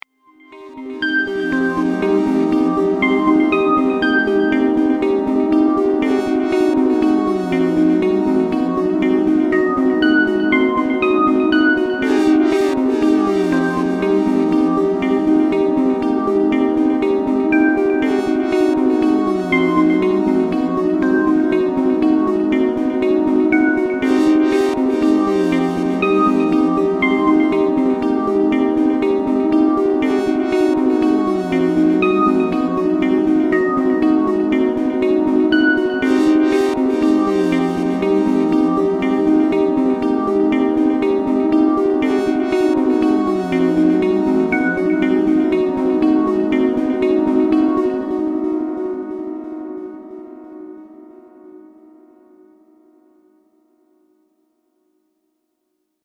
Pieza de electrónica minimalista clásica
Música electrónica
melodía
minimalista
rítmico
sintetizador